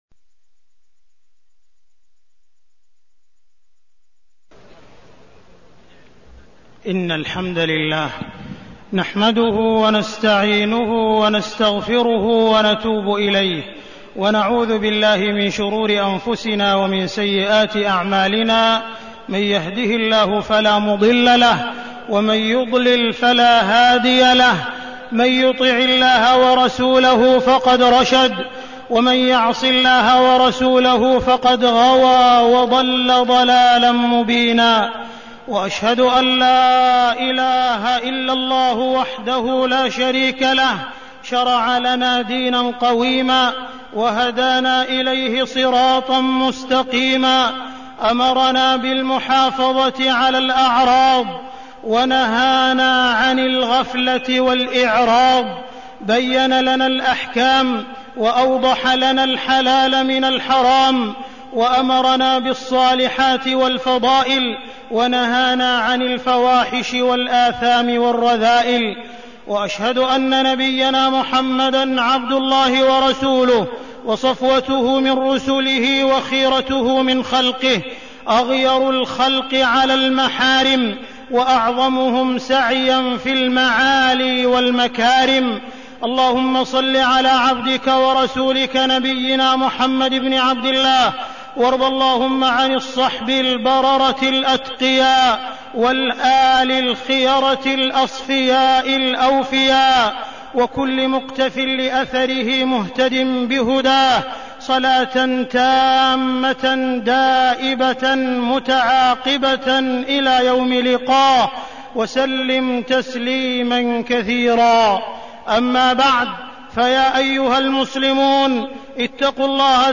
تاريخ النشر ١ شعبان ١٤١٩ هـ المكان: المسجد الحرام الشيخ: معالي الشيخ أ.د. عبدالرحمن بن عبدالعزيز السديس معالي الشيخ أ.د. عبدالرحمن بن عبدالعزيز السديس حماية الأعراض والأمراض الجنسية